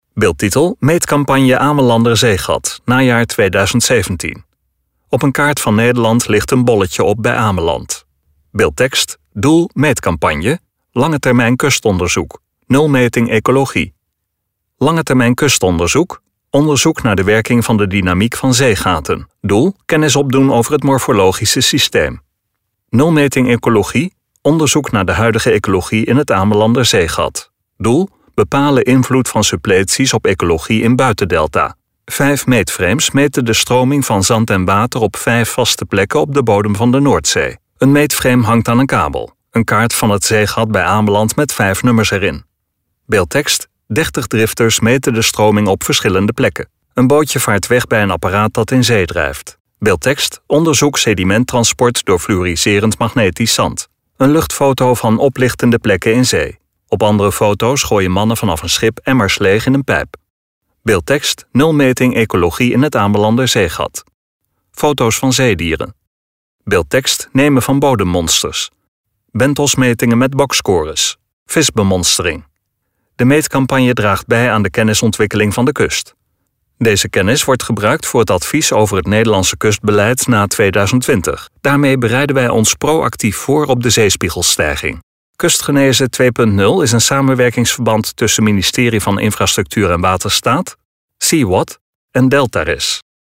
LEVENDIGE MUZIEK TOT HET EIND VAN DE VIDEO (Beeldtekst: DOEL meetcampagne: Langetermijn kustonderzoek.